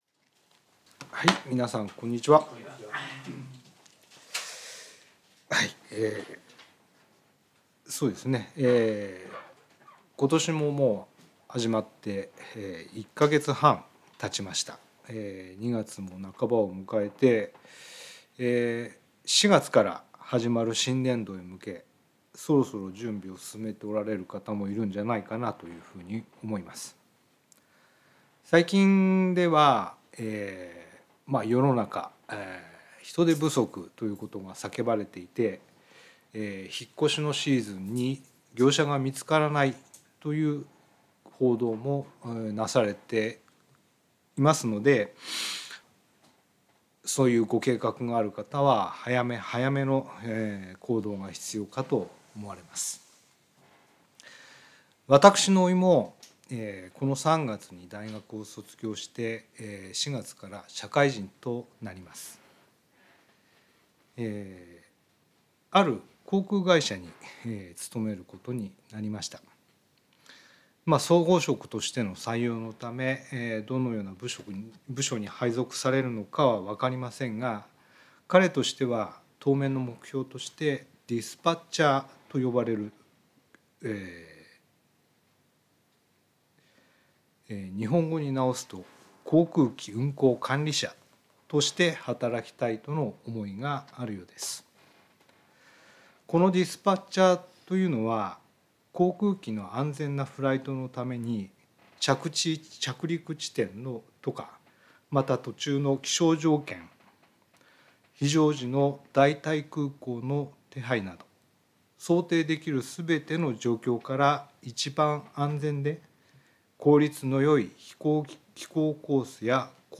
聖書メッセージ No.255